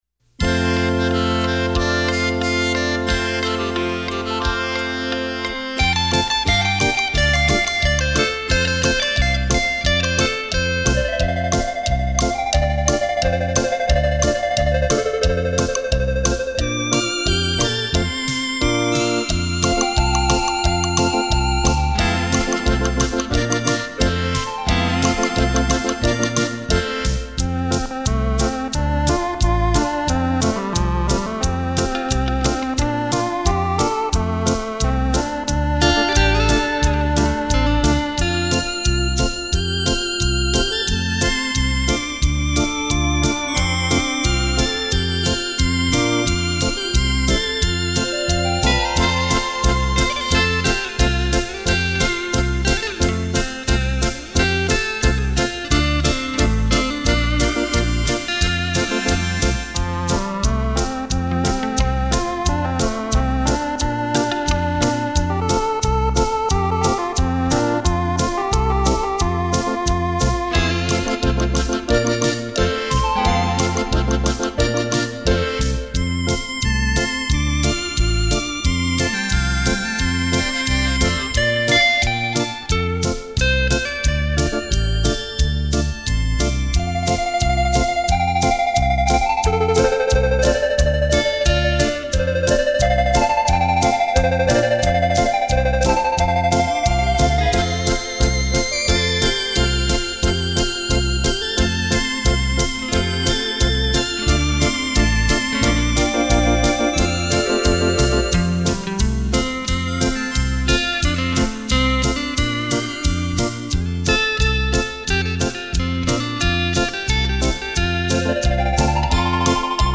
超强环绕音效